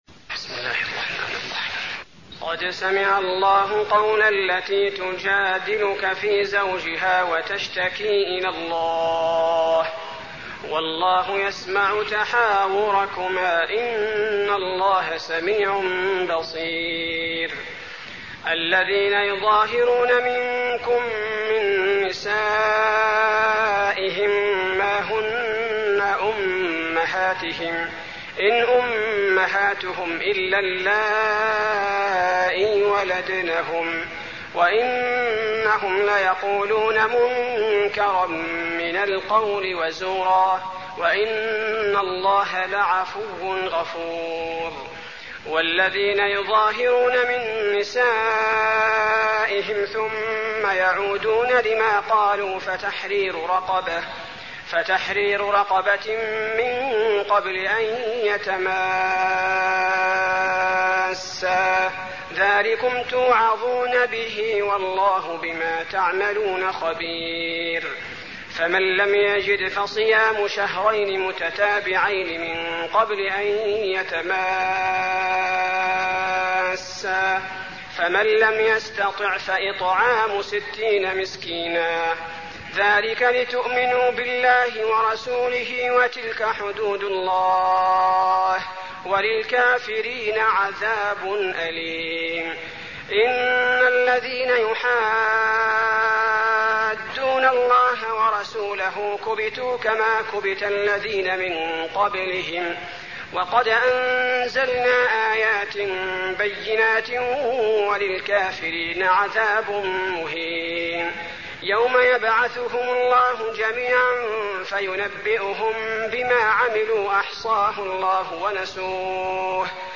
المكان: المسجد النبوي المجادلة